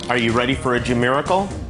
Tags: parody commercial Jimmy Kimmel Jimmy Kimmel diet hottie body diet dit